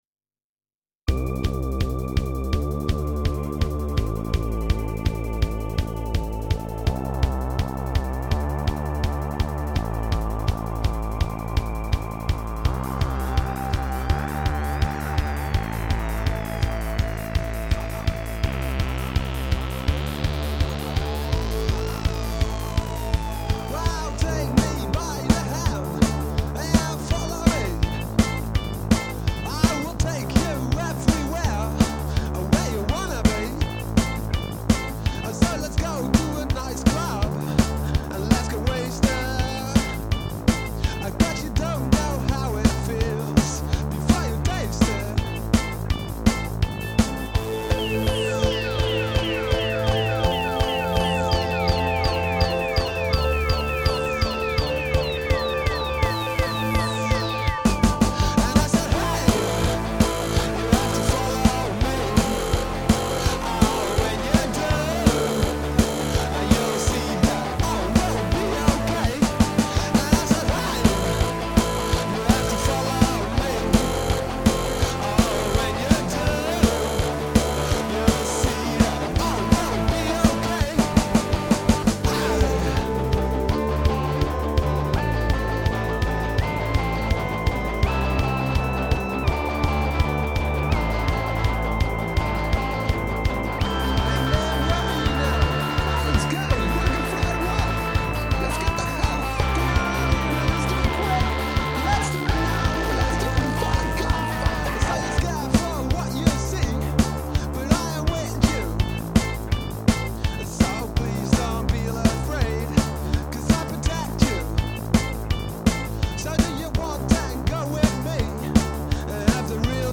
Opzwepende track